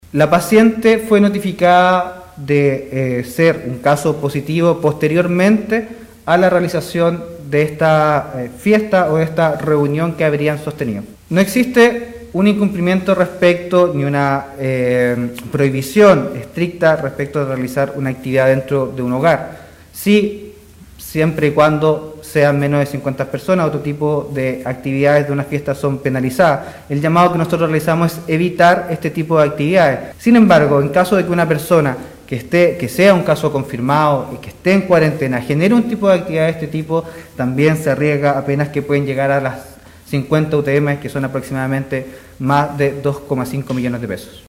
Esta situación fue abordada durante la jornada de este miércoles por el seremi de salud de Atacama, Bastian Hermosilla, en el habitual punto de prensa que realizan las autoridades regionales en esta etapa de Estado de excepción en el país.